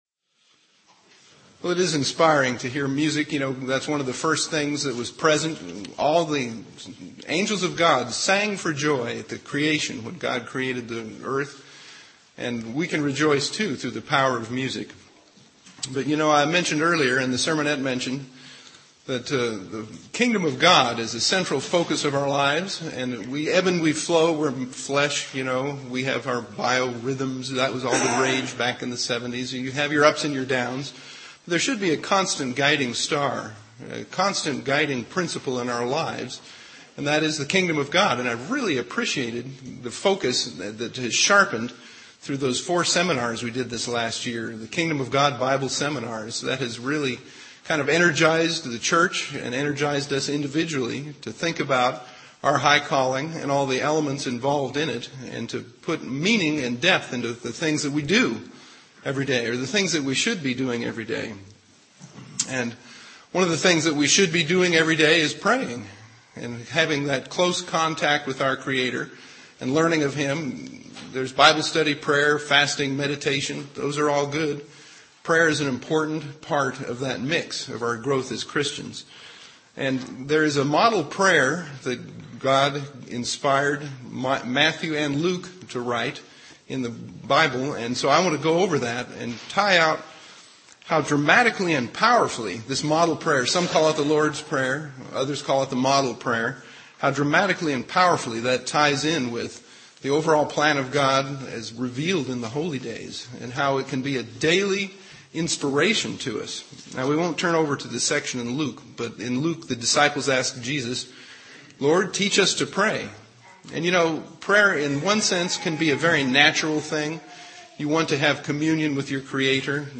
Given in Ft. Wayne, IN
UCG Sermon Studying the bible?